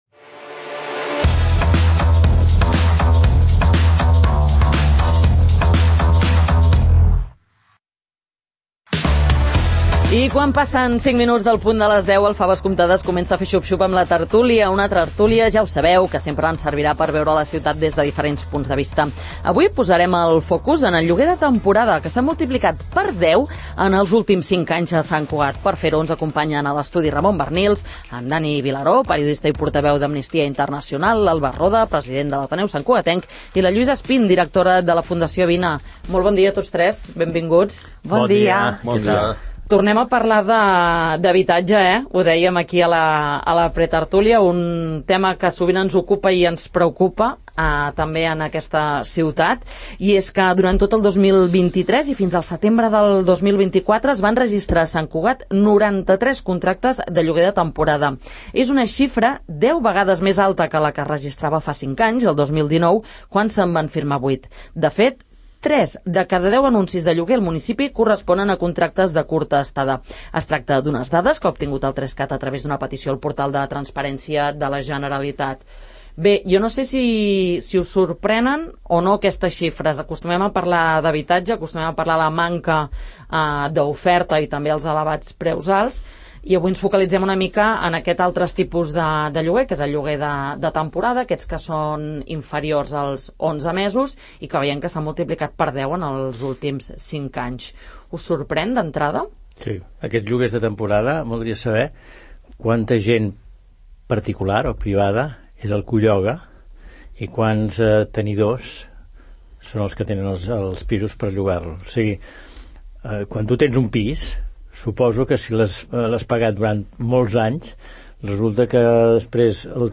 Quin efecte tenen en l’accés a l’habitatge els lloguers de temporada? En parlem a la tertúlia del ‘Faves comptades’